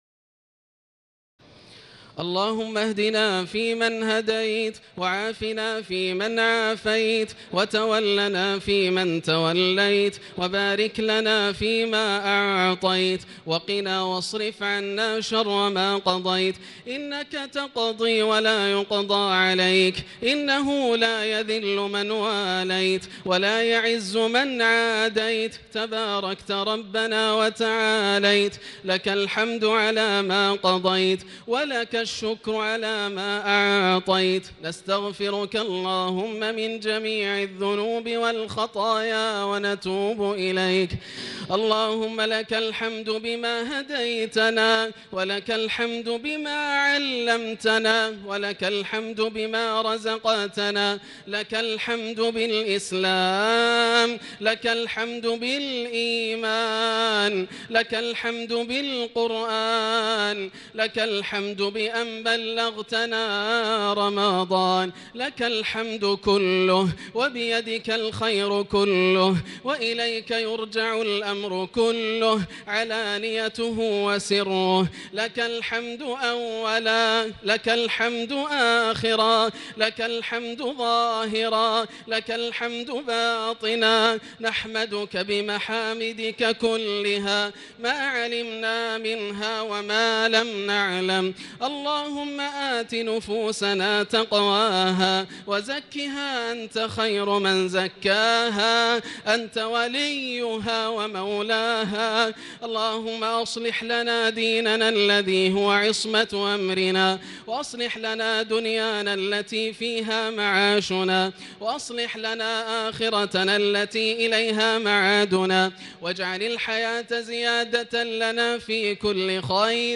دعاء القنوت ليلة 5 رمضان 1439هـ | Dua for the night of 5 Ramadan 1439H > تراويح الحرم المكي عام 1439 🕋 > التراويح - تلاوات الحرمين